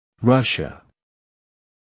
Rus·sia /ˈrʌʃ.ə/ター・タ ＝ 母音2つ（2シラブル）